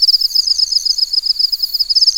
Index of /90_sSampleCDs/Roland LCDP11 Africa VOL-1/SFX_Afro Jungle/SC _Afro Jungle
AN  CRICKE02.wav